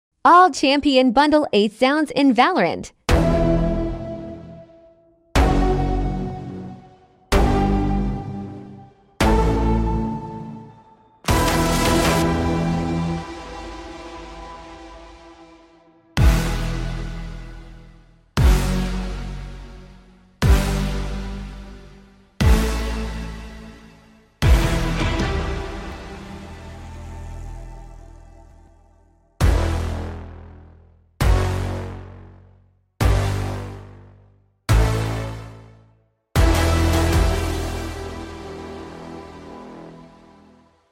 All Champion Ace Sounds in sound effects free download
All Champion Ace Sounds in Valorant